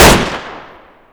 c96_shoot.wav